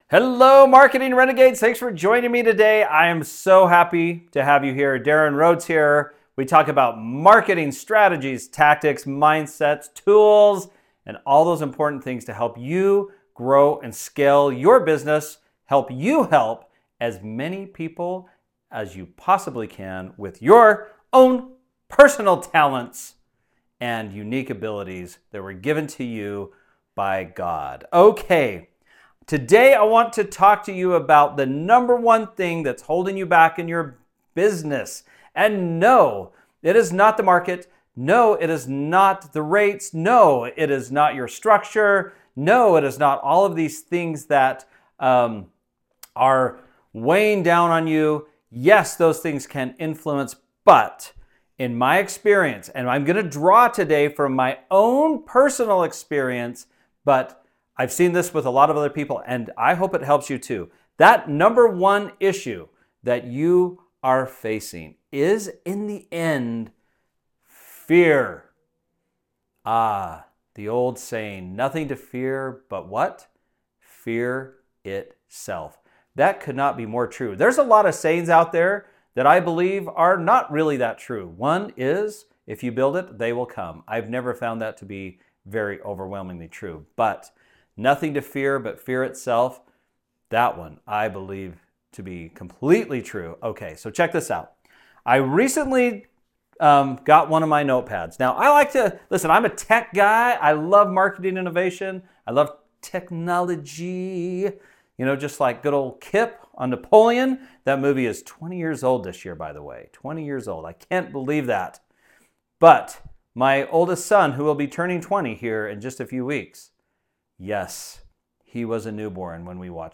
I’m reading from my journal in real-time today, and as embarrassing as it may be, I think you’ll relate.